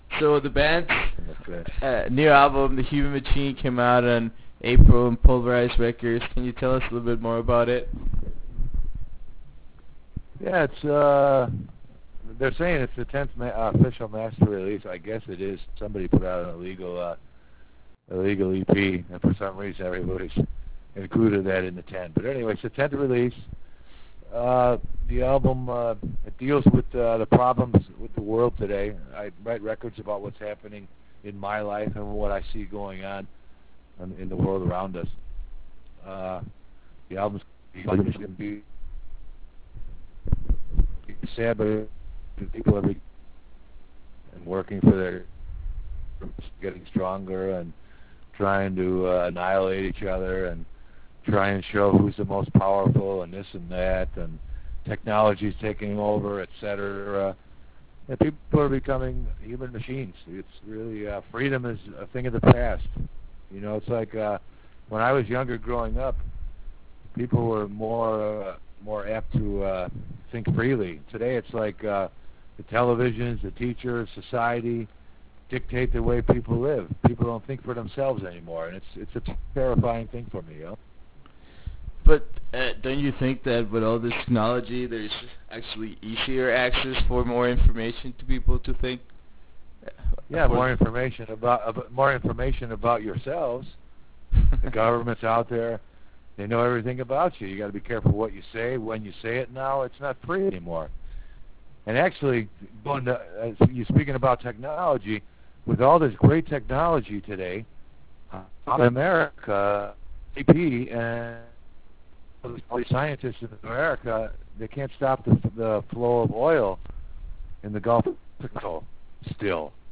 Interview with Master